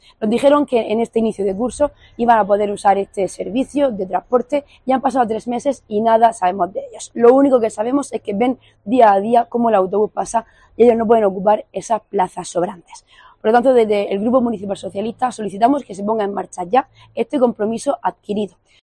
Irene Jódar, concejala PSOE Ayto Lorca